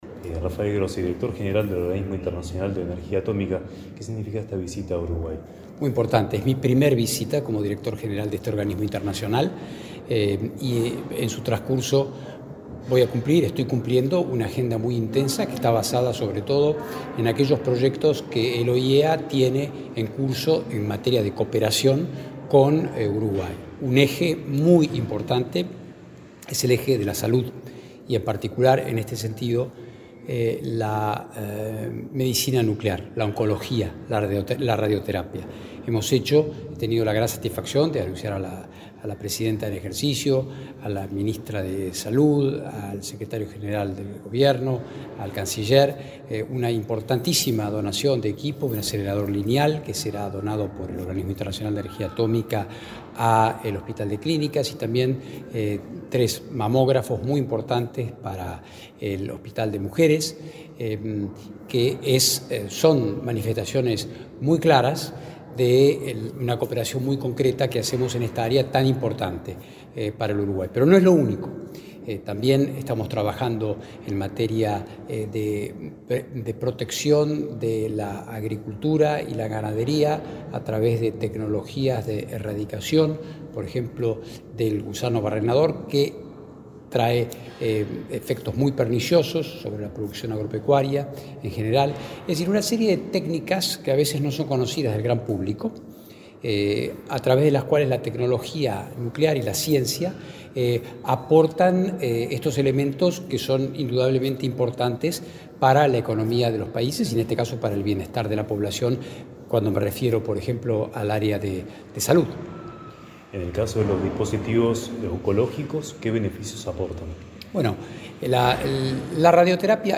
Entrevista a Rafael Grossi, director general de OIEA